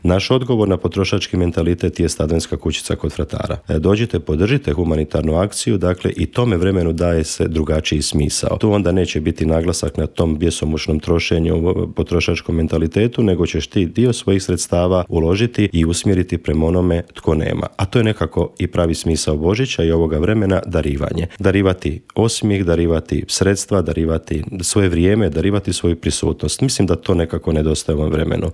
u intervjuu Media servisa kako građani mogu sudjelovati u toj humanitarnoj akciji